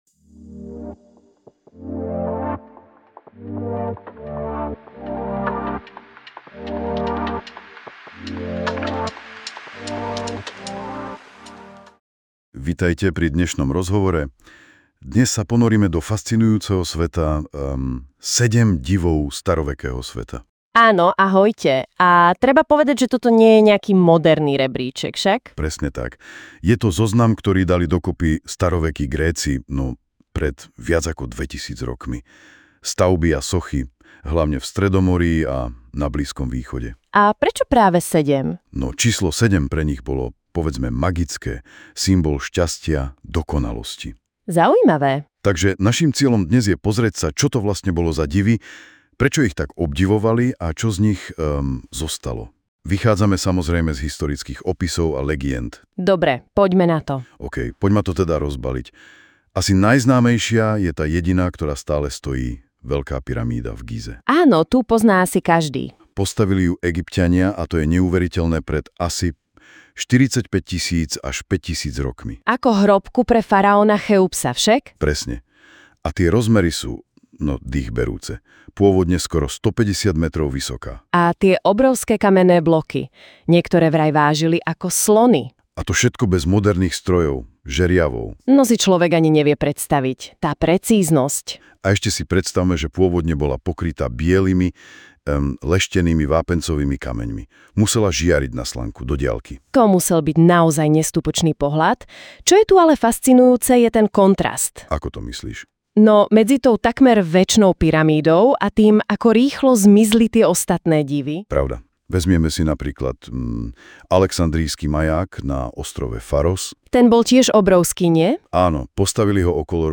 Obaja digitálni moderátori podcastu nás formou rozhovoru prenesú do dôb dávno minulých a priblížia nám Sedem divov starovekého sveta.
Našu základnú zvukovú nahrávku (vo formáte WAU) doplnil o úvodnú a záverečnú hudobnú sekvenciu, sfinalizoval ju do formátu MP3 a predložil na stiahnutie.
Obaja moderátori sú digitálne vygenerovaní.